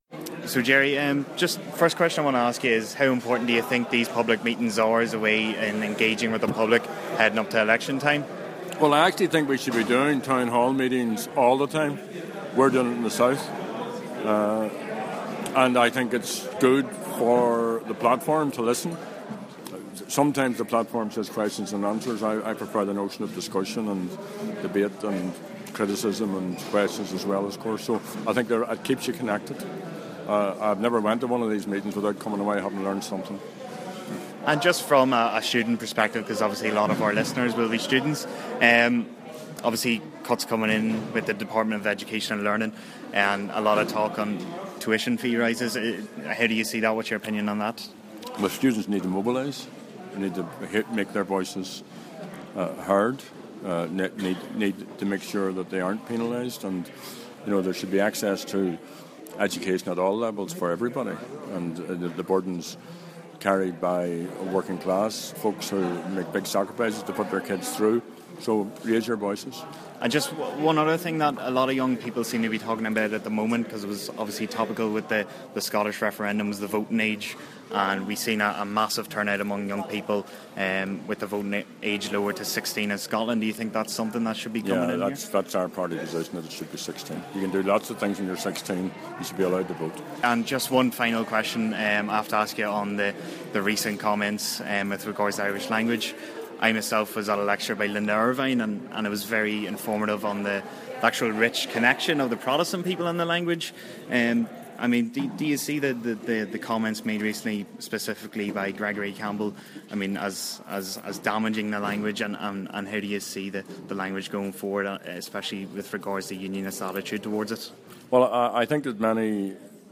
Interview with Gerry Adams